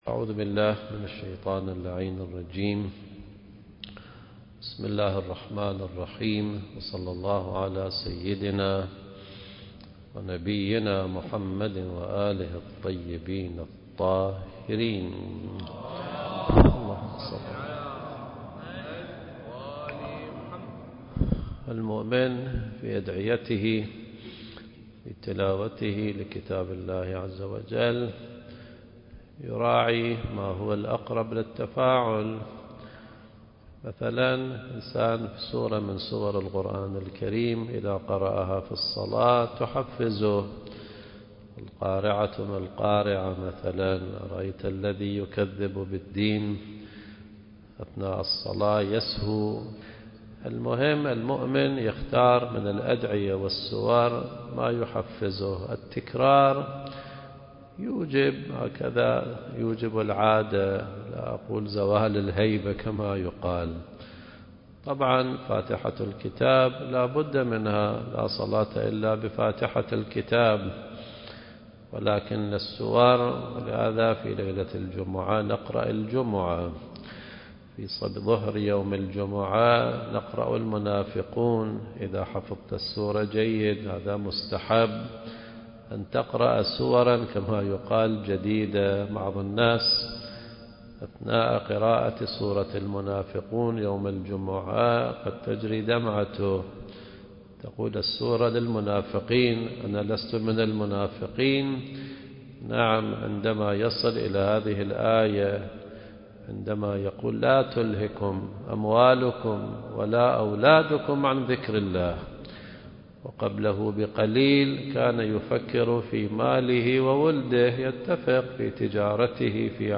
المكان: مسجد الصديقة فاطمة الزهراء (عليها السلام)/ الكويت